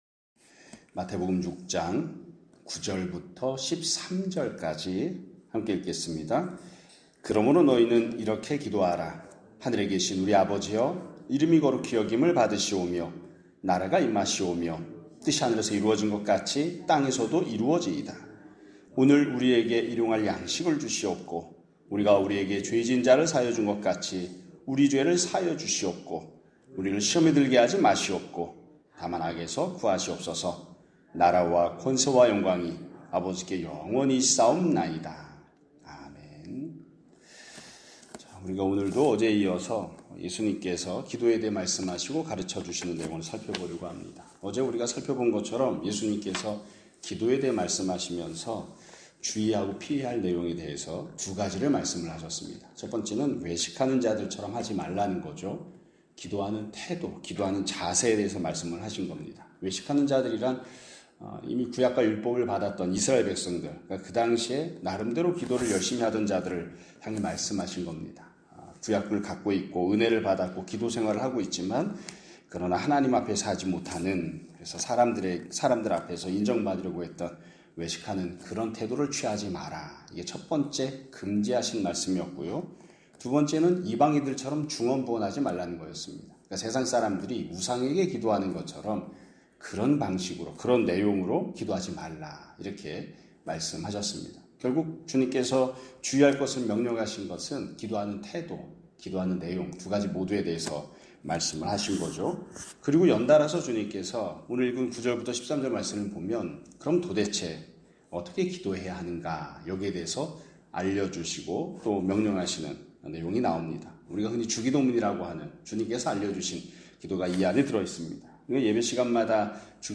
2025년 6월 13일(금요일) <아침예배> 설교입니다.